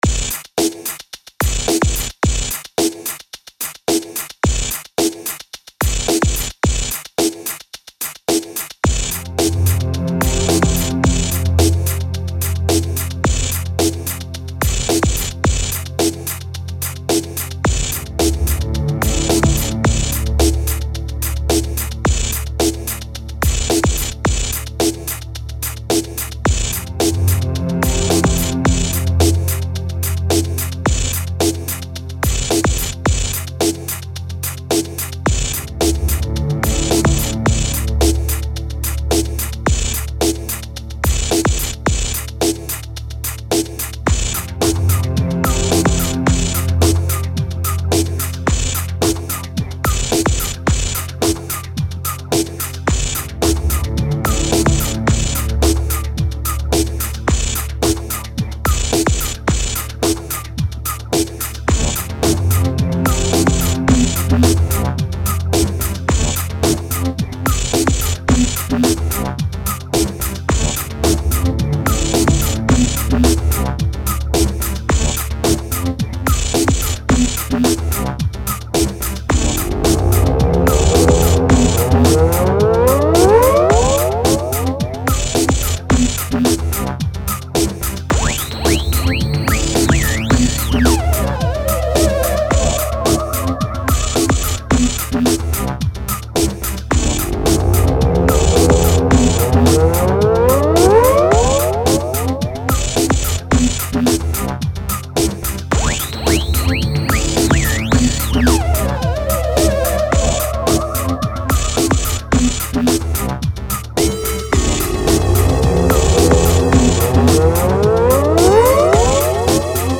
Drum & Bass